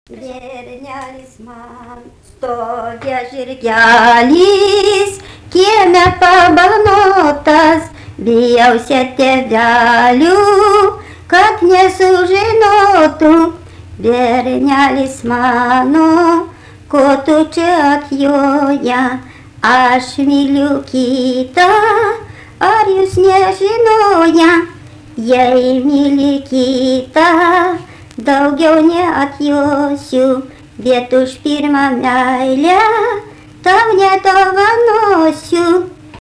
daina, vestuvių